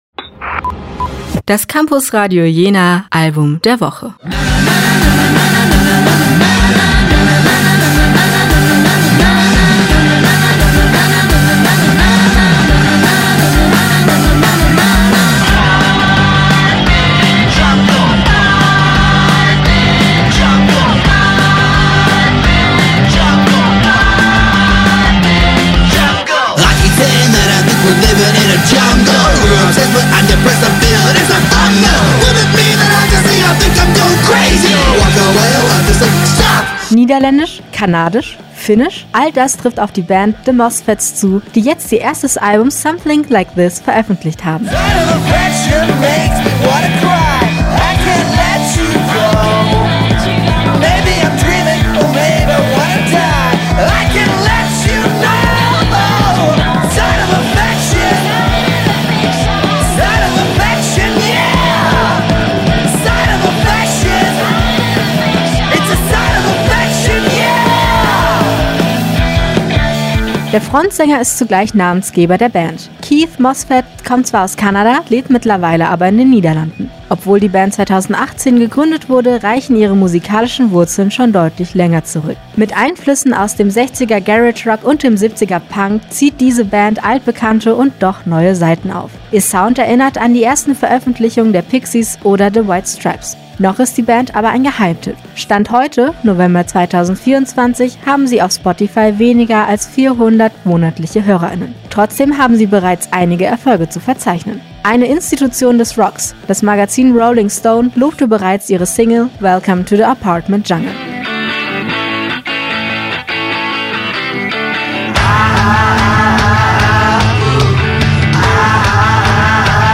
Mit Einflüssen aus dem 60er Garage Rock und dem 70er Punk zieht diese Band altbekannte und doch neue Saiten auf.
Insgesamt überzeugt das Album aber durch seine schnellen Rhythmen, die dominante E-Gitarre und den klassisch roh-heiseren Klang.
The Mosfets mit ihrem Album “Something Like This” – unser Campusradio Jena Album der Woche!